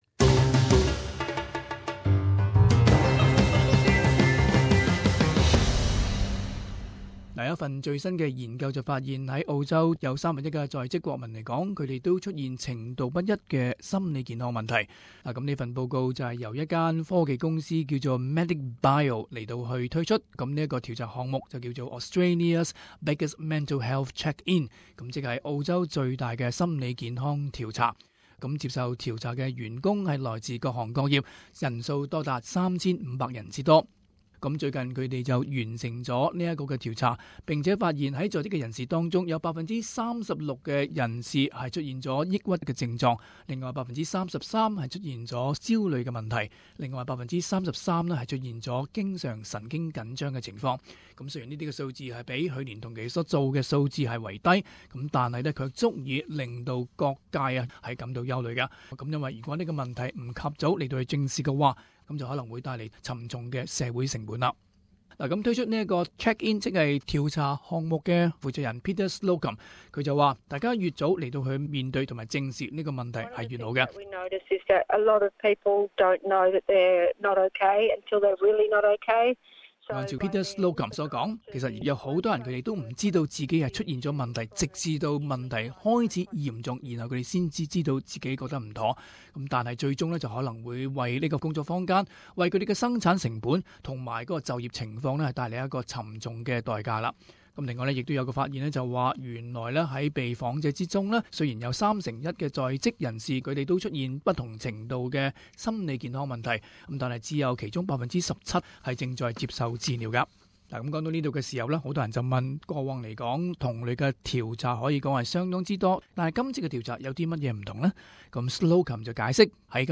【時事報導】超過三份一在職人士出現心理健康問題